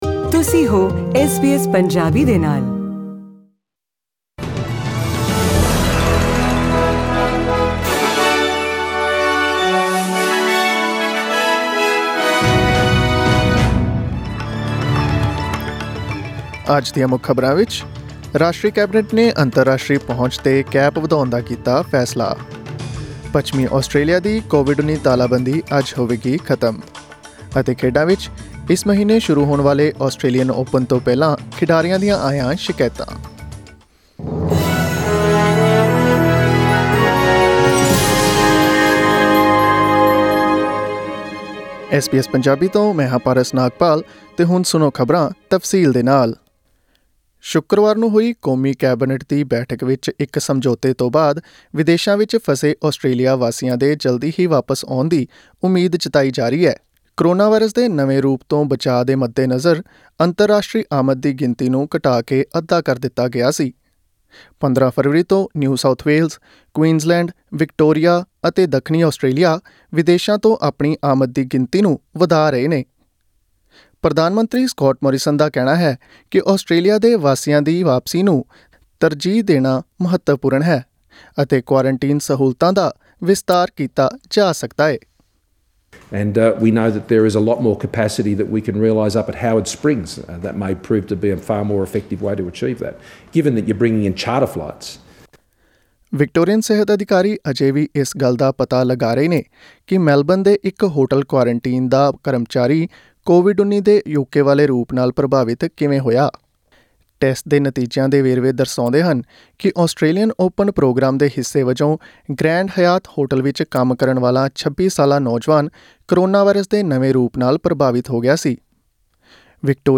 In this bulletin: National Cabinet decides to increase the cap on international arrivals, Western Australia's COVID-19 lockdown to end on Friday night and in the tennis, More player complaints ahead of the Australian Open starting this month.